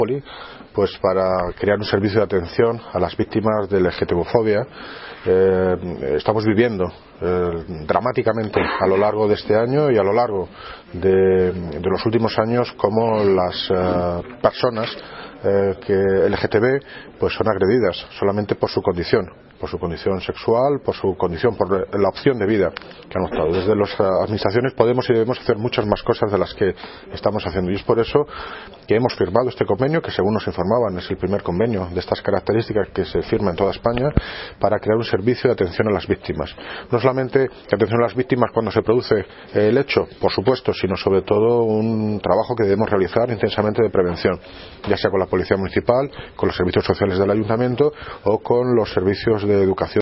Audio - David Lucas (Alcalde de Móstoles) Sobre Convenio con ARCOPOLI